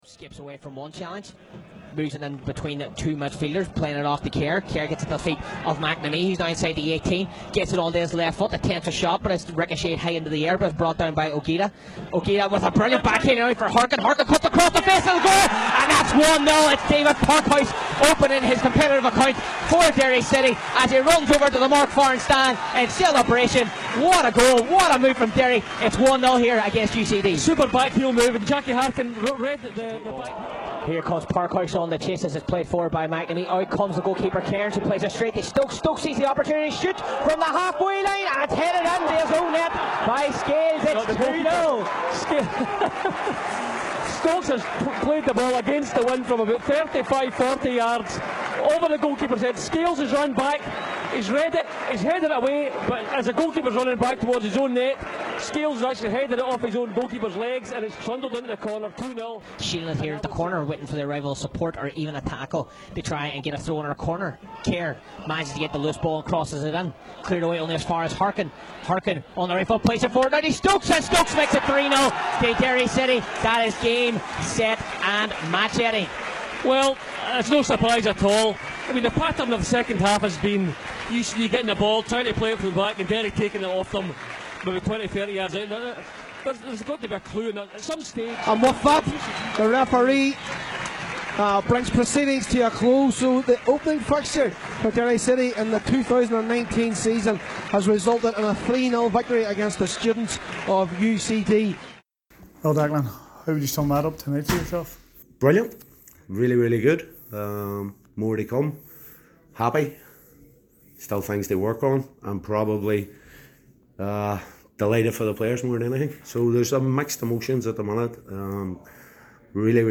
Derry City 3-0 UCD Goal Highlights